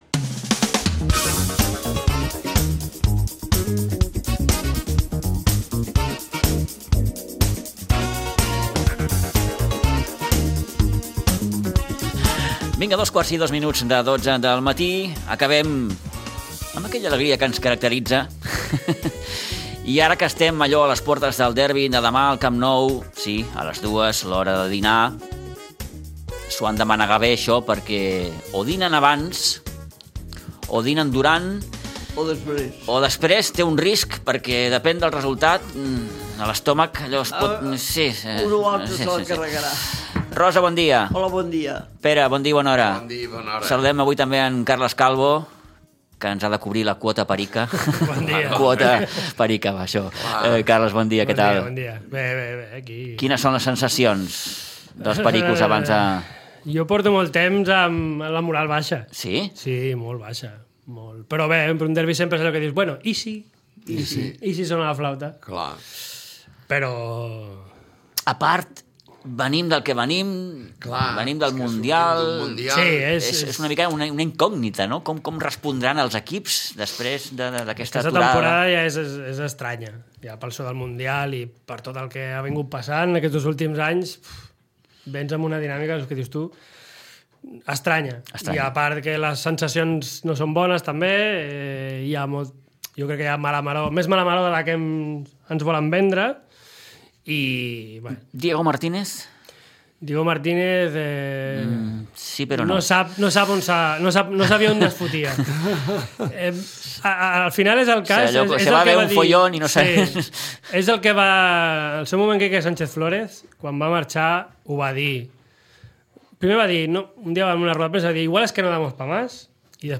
La tertúlia esportiva dels divendres